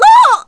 Morrah-Vox_Damage_kr_02.wav